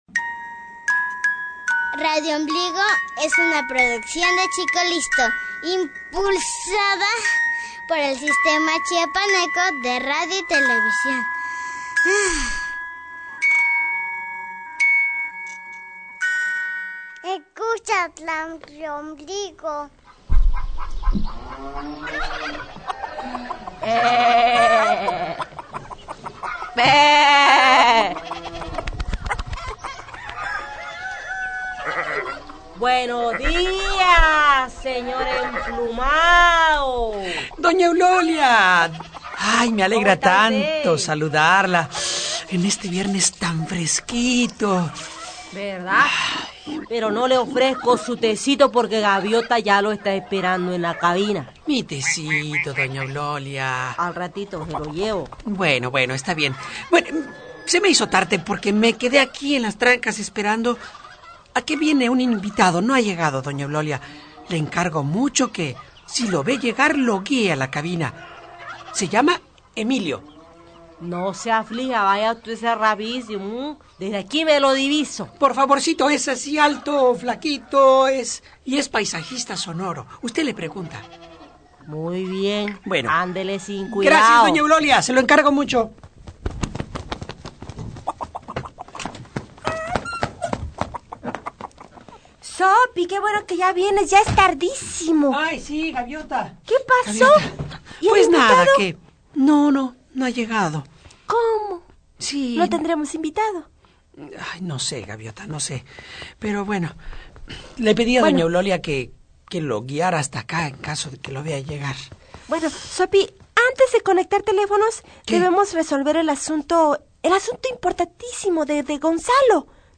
El viernes 11 de julio de 2008 visitamos el programa Infantil de Radiombligo donde presentamos una cajita musical muy especial ya que dentro contiene un Claro de Luna del compositor Claude Debussy, y esta caja fue regalada al que ahora es un guía espiritual de muchas personas el Dalái Lama.
Así tambien se presentó la versión completa orquestal de dicha pieza, Agradecemos a todo el equipo de Radiombligo el que nos hayan abierto el estudio y su mundo allá en el Cerro del Rebote desde este espacio virtual les enviamos un cariñoso saludo.